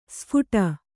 ♪ sphuṭa